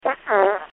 Tags: funny comedy podcast radio disgusting